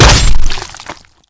zombie_attack_1.wav